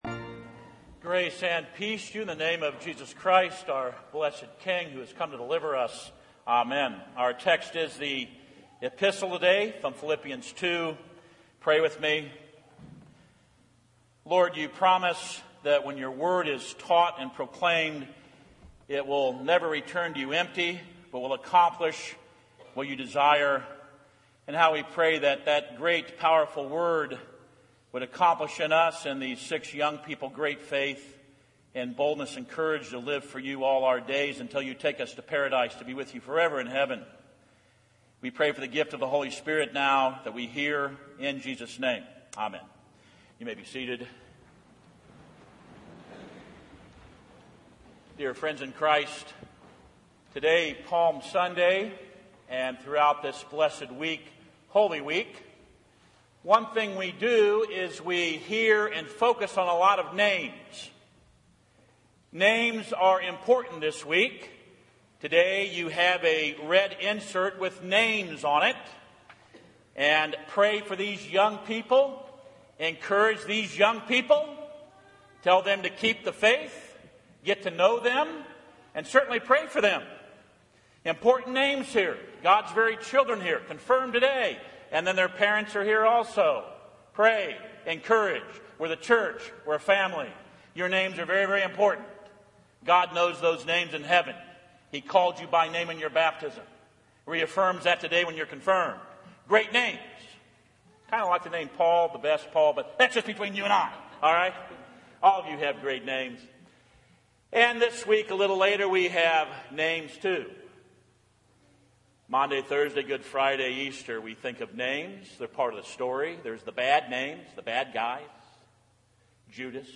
Philippians 2:5-11 Audio Sermon